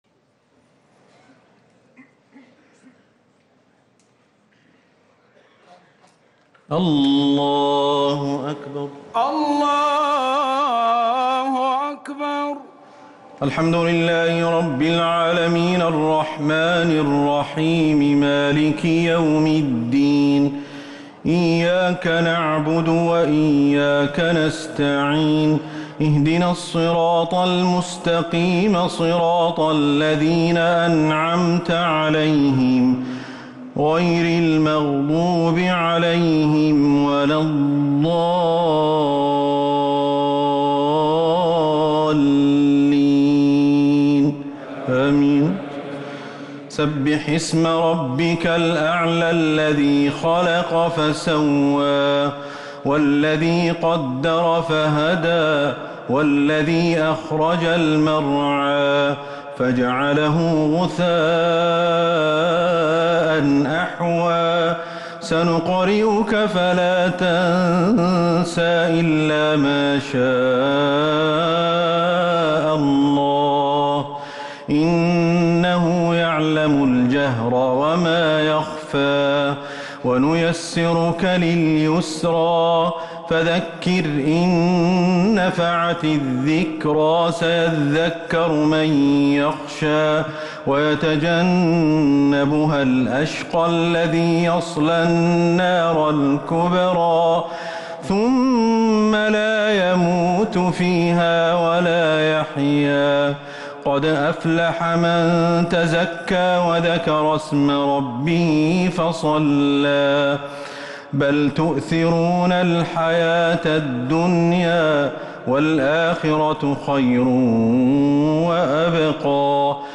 صلاة الشفع والوتر مع دعاء القنوت ليلة 16 رمضان 1444هـ > رمضان 1444هـ > التراويح - تلاوات الشيخ أحمد الحذيفي